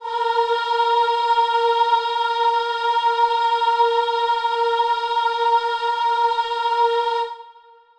Choir Piano
A#4.wav